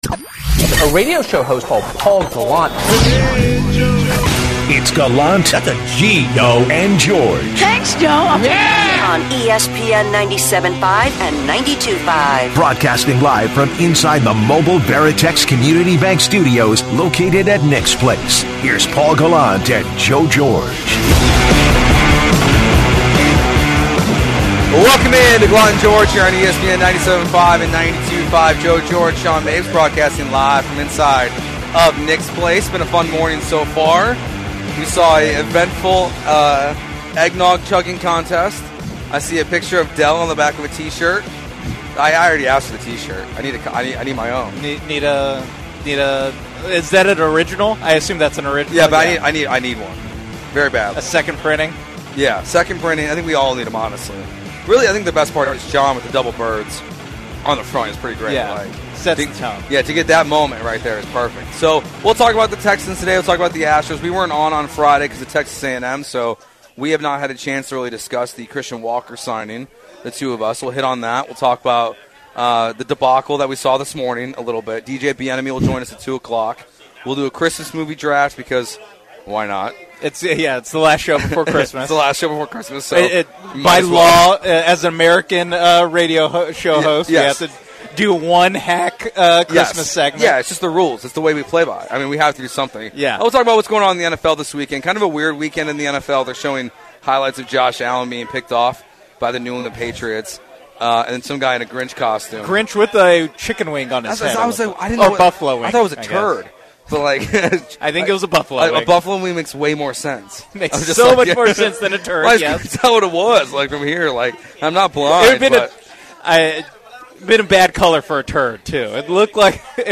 This hour, LIVE from the ESPN 97.5 Christmas Party, featured...